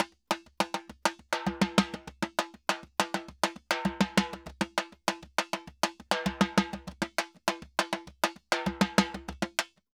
Timba_Salsa 100_2.wav